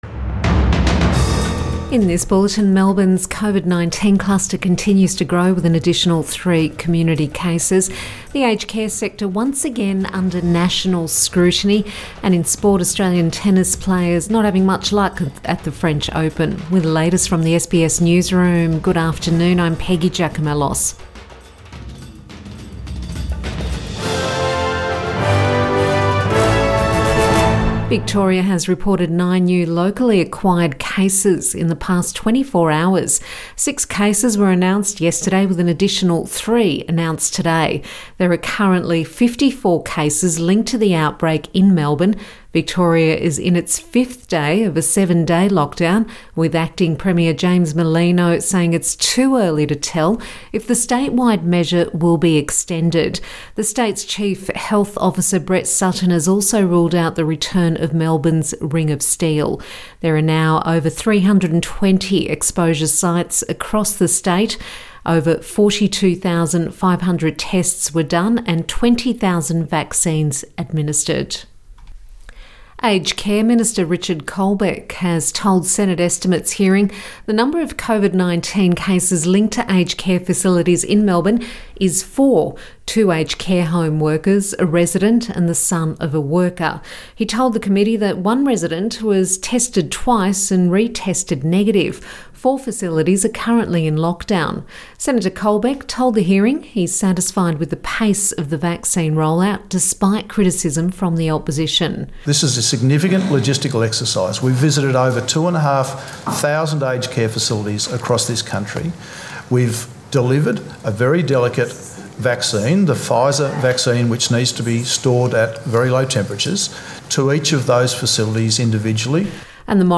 Midday bulletin June 1 2021